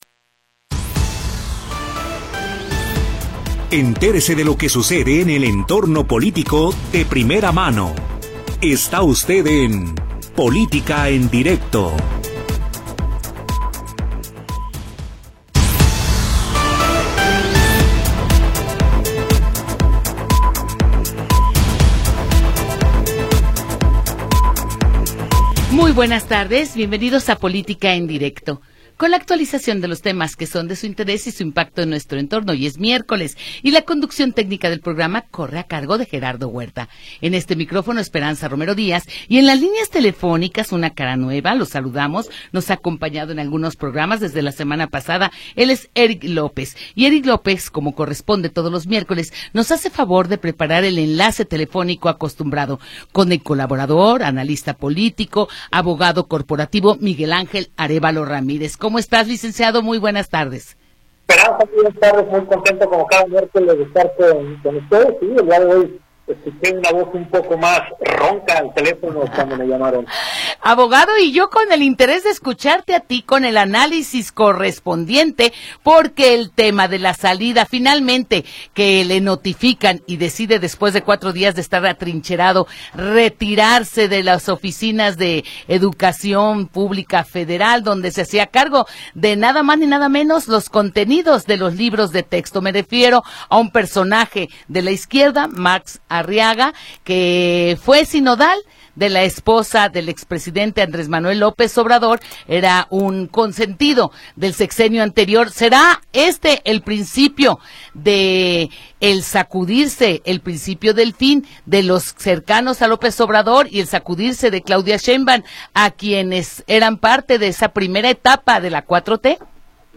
Entérese de todo lo que sucede en el entorno político. Comentarios, entrevistas, análisis